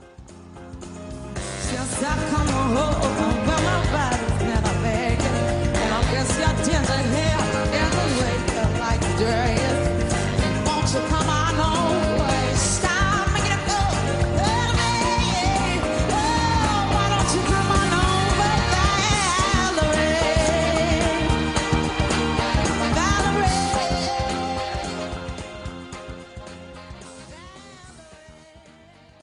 Amy Winehouse at the BRIT's